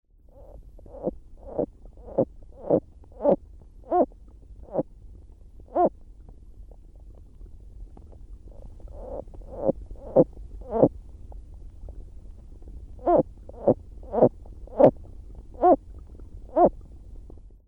It is a short and rasping call often accelerated and rising at the end, sometimes preceded by calls that don't rise at the end.
Water flowing from snow-melt into the small lake can be heard in the background, along with occasional singing birds.
Sound This is 18 second recording of calls made by the same frog heard on the left, but recorded with an underwater microphone placed in the shallow water in front of the frog. The sounds recorded are those produced by the frog underwater.